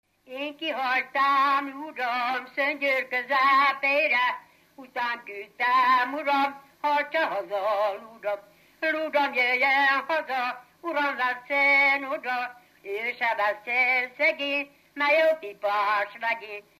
Erdély - Udvarhely vm. - Korond
ének
Stílus: 7. Régies kisambitusú dallamok
Szótagszám: 6.6.6.6
Kadencia: 4 (b3) 4 1